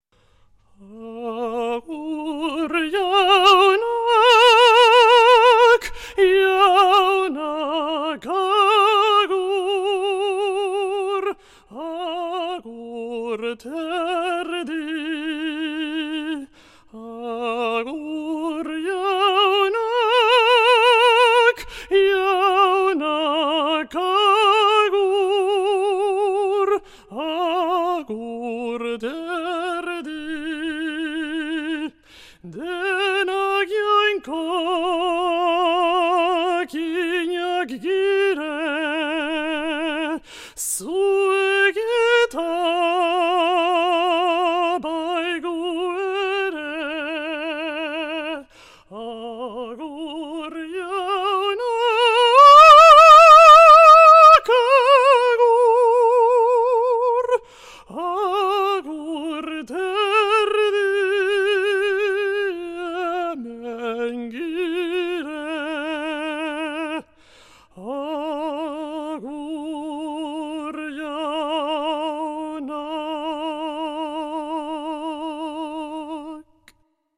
'Agur jauna' kontratenore baten ahotsean